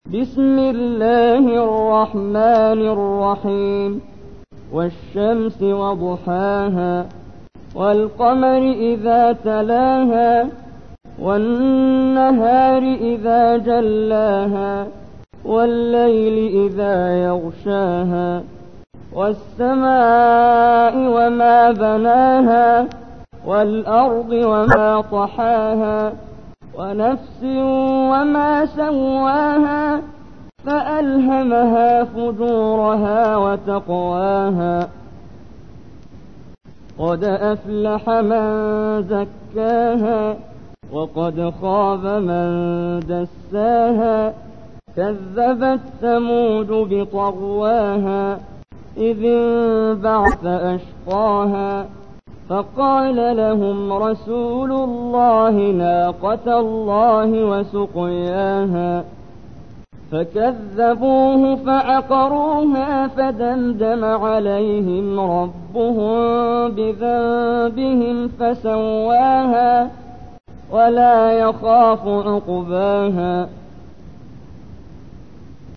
تحميل : 91. سورة الشمس / القارئ محمد جبريل / القرآن الكريم / موقع يا حسين